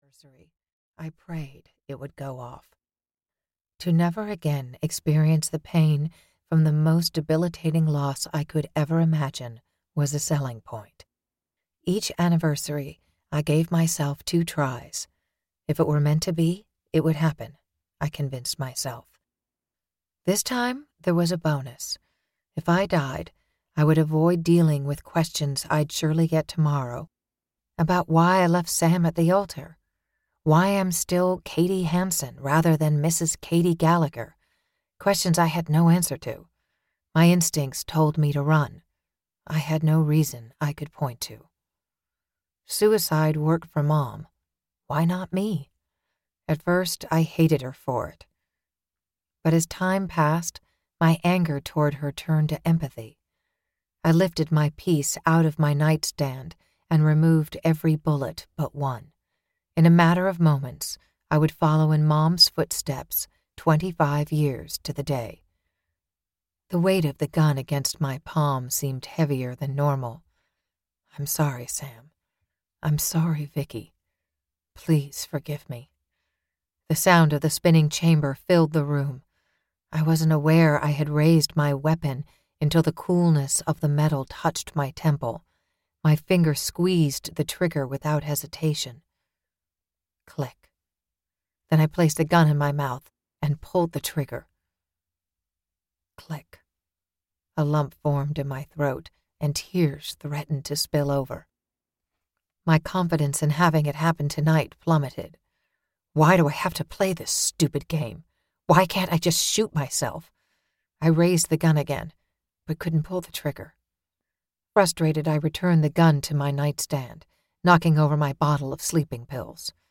Streaming Sarah (EN) audiokniha
Ukázka z knihy